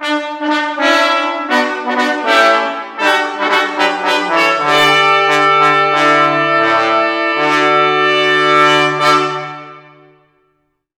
fanfara_11s.wav